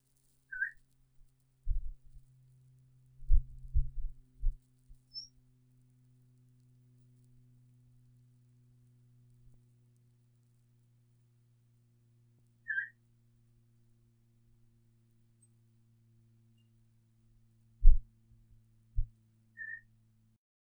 Boreal Owl
Location: Soapstone Creek Area of the Uinta Mountains
Song or call & method of delivery:
This bird sounded similar to what I’ve heard from some females as they interacted with smaller males in close proximity during fall observations that I’ve made in the past several years. It made a soft “wert” sound that was sometimes followed by a soft, high-pitched “eep” note.
It vocalized softly on several occasions.
Habitat: Mixed high-elevation forest (spruce, fir, and scattered aspen)